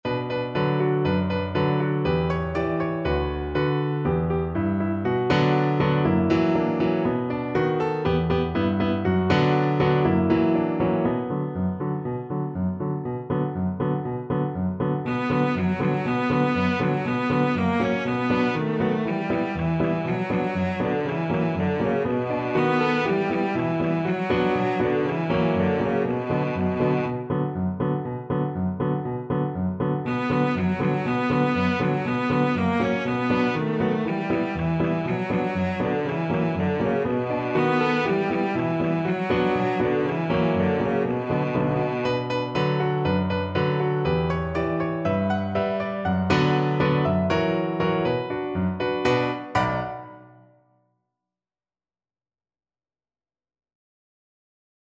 Traditional Music of unknown author.
Allegro (View more music marked Allegro)
2/4 (View more 2/4 Music)